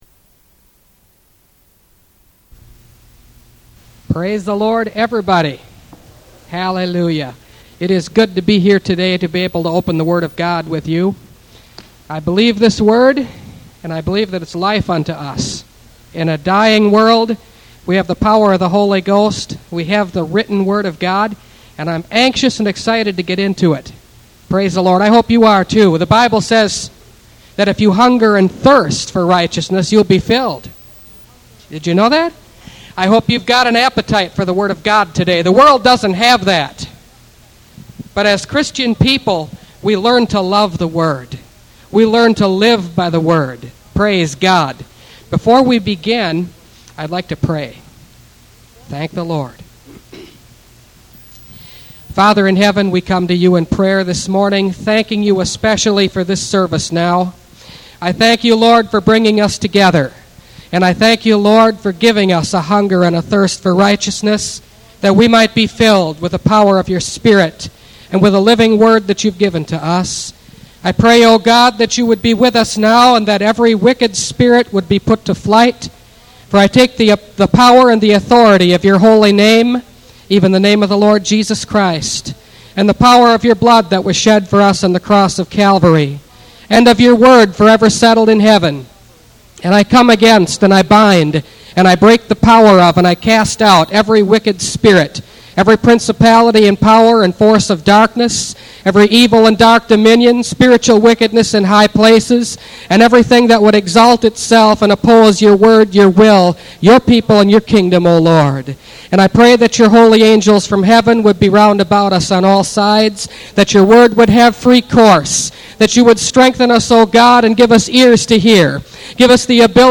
Revelation Series – Part 36 – Last Trumpet Ministries – Truth Tabernacle – Sermon Library